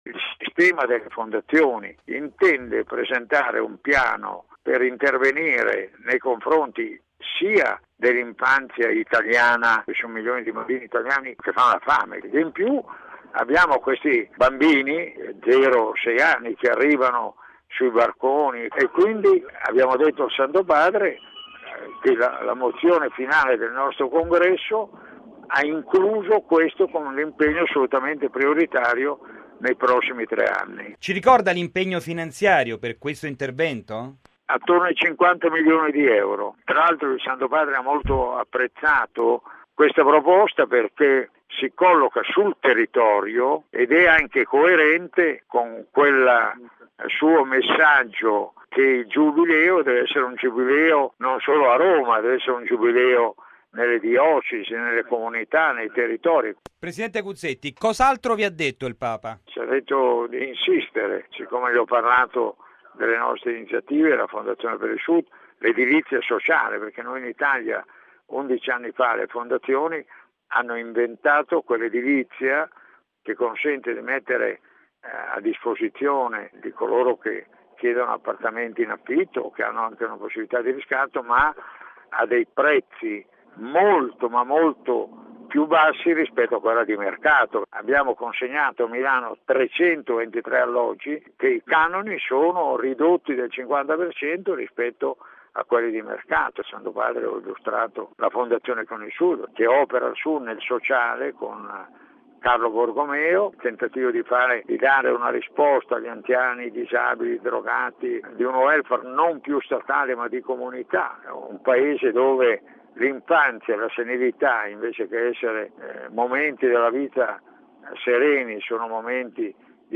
intervistato